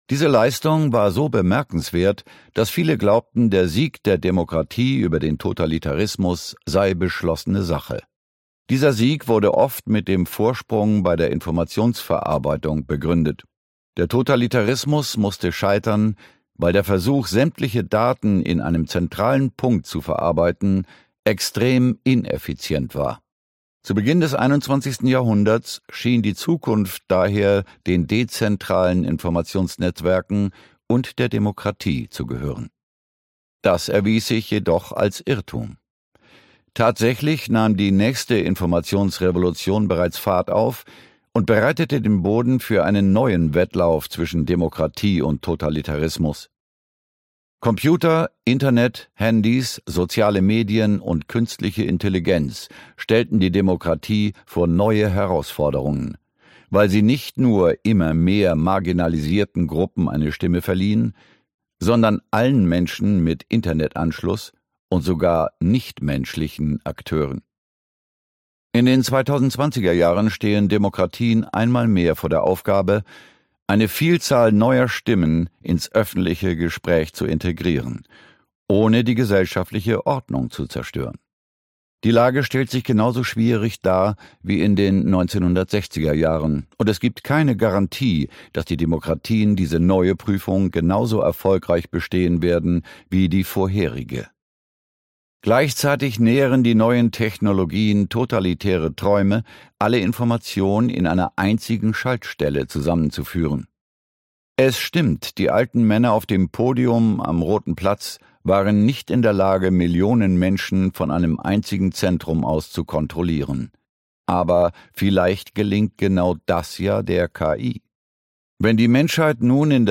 »Nexus« erkundet den hoffnungsvollen Mittelweg zwischen diesen Extremen und zeigt, wie sich unser gemeinsames Menschsein wiederentdecken lässt. Ausgabe: Ungekürzte Lesung, Hörbuch Download, Hördauer: 19h 30min Sprecher*innen